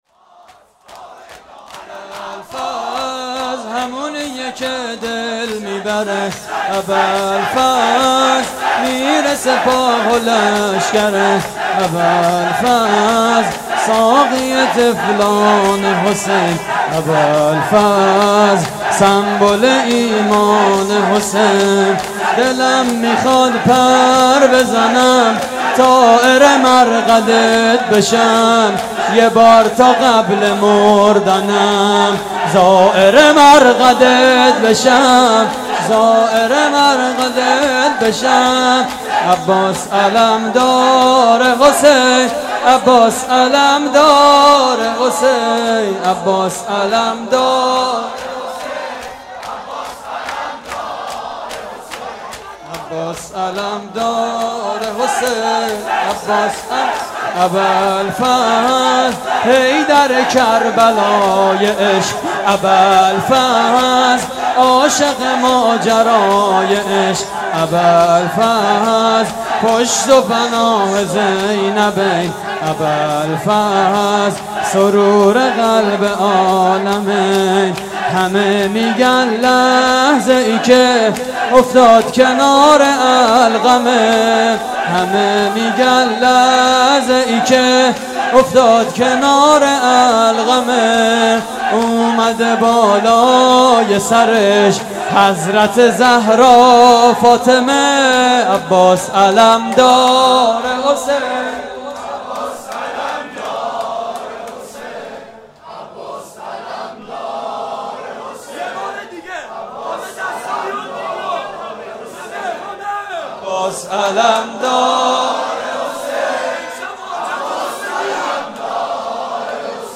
صوت مراسم:
شور